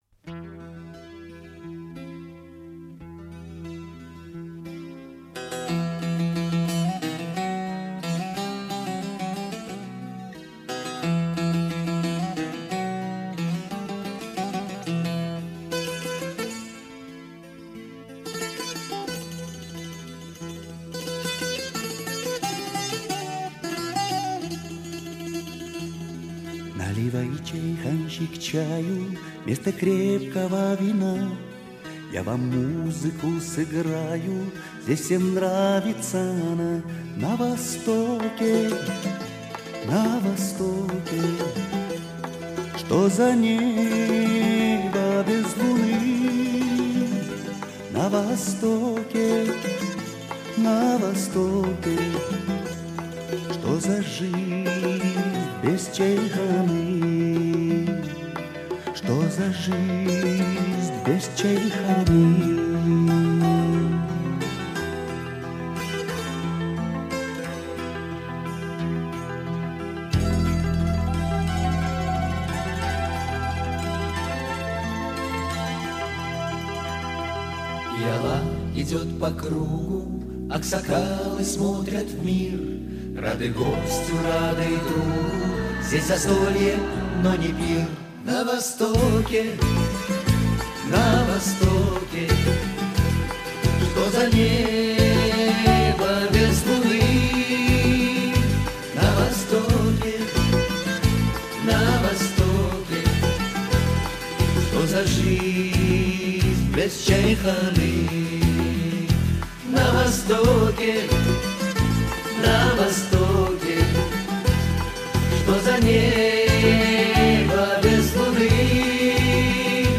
虽是俄语歌曲，却洋溢着中亚的民族色彩。